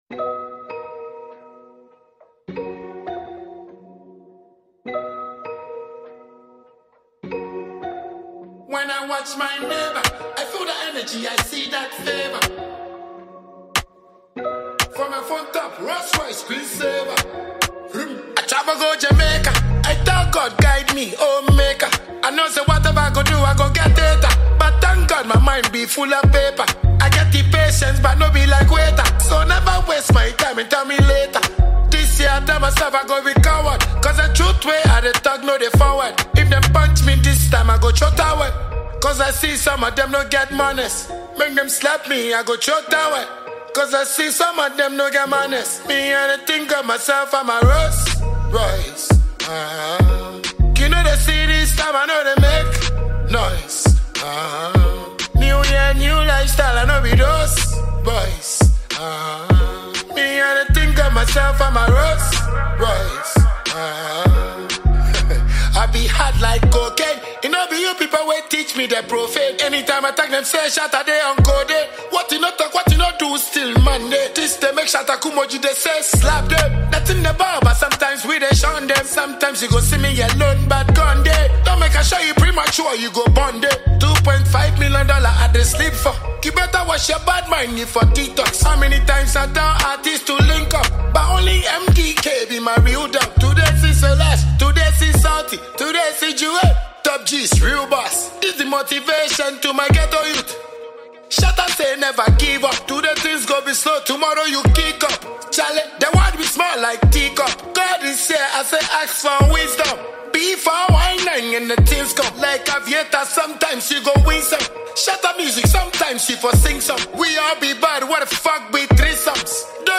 Ghanaian dancehall musician